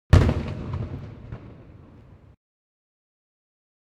snd_explo1.ogg